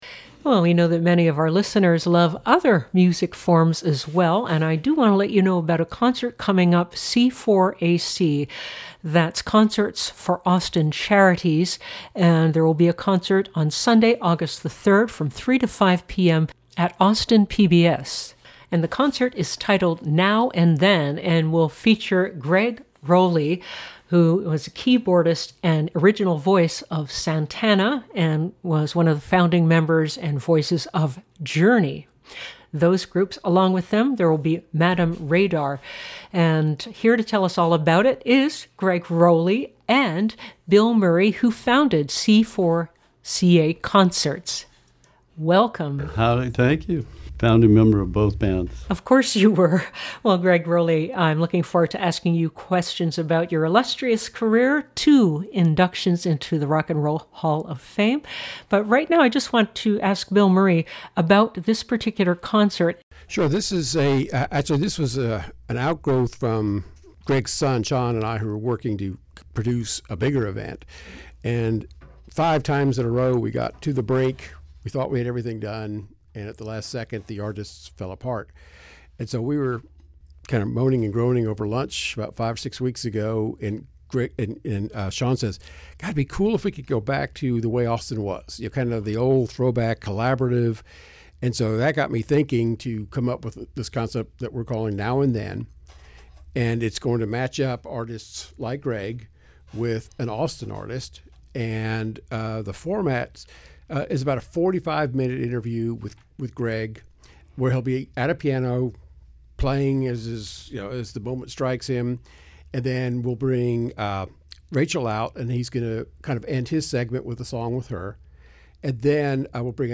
Here's the full conversation: